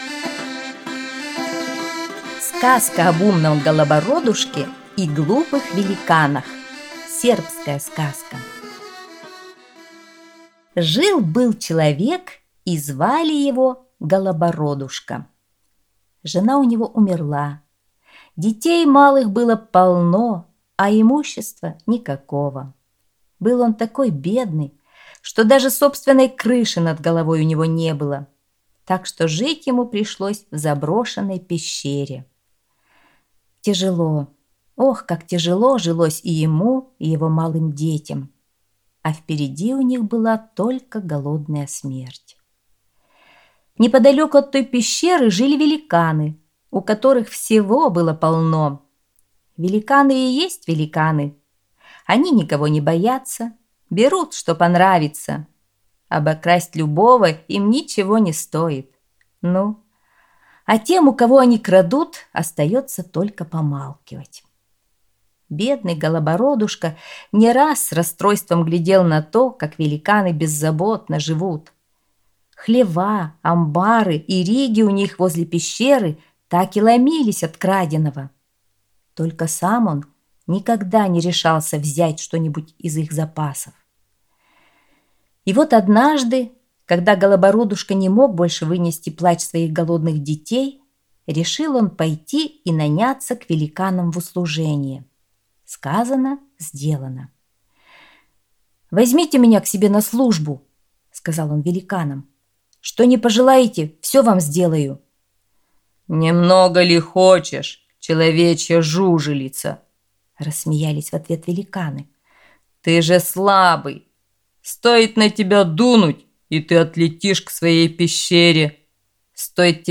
Сказка об умном Голобородушке и глупых великанах - сербская аудиосказка